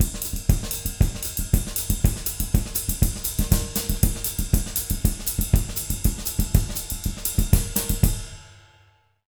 240SAMBA02-L.wav